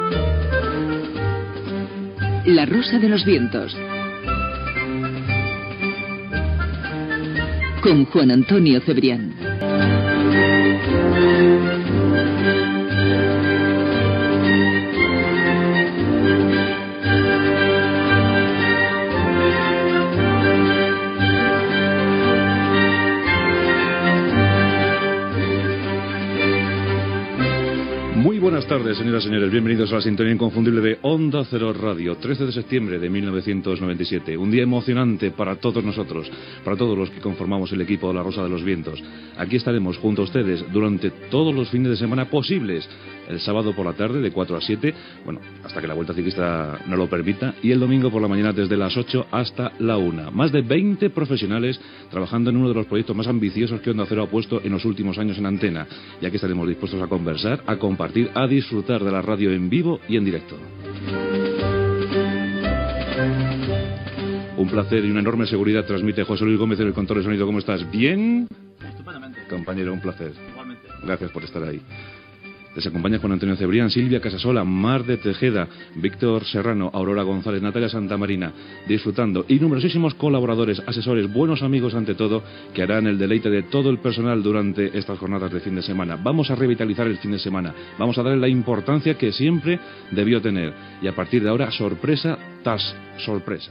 Careta del programa, data i presentació.
Entreteniment